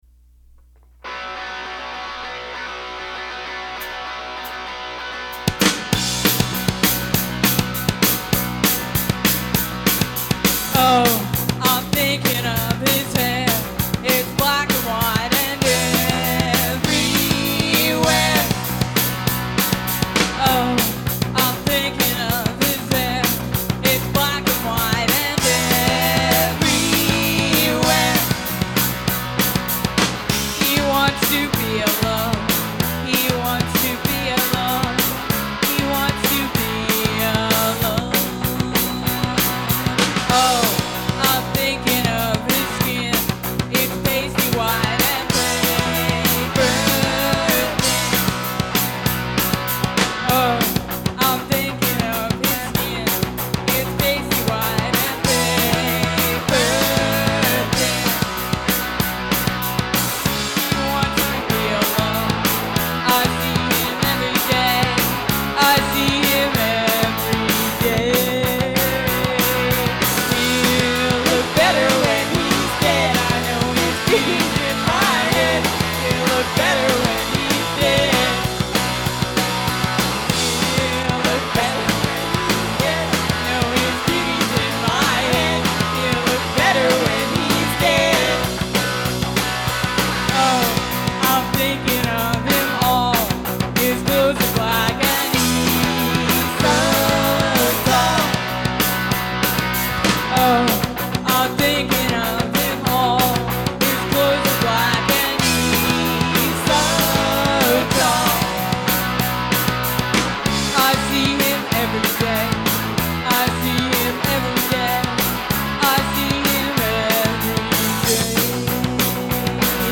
Recording of a live performance